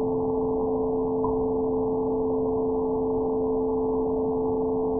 用喇叭播放音符C3和A4组合，平台连接外部麦克风，然后连续地对麦克风的输出进行采样(模数转换(ADC)采样)并保存声音，并通过傅立叶变换得到声音的频率信息。C3的频率为261Hz，A4的频率为440Hz。
该声音录制系统使用2000Hz的采样频率，5V的参考电压。
因此2000Hz的采样频率足够检测出喇叭中播放音符的C3(261Hz)和A4(440Hz)。